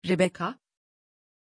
Pronunția numelui Rebecka
pronunciation-rebecka-tr.mp3